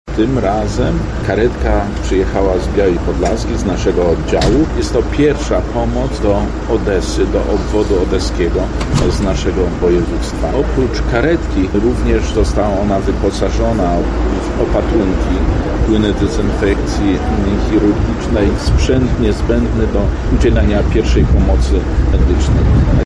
-mówi wicemarszałek Zbigniew Wojciechowski.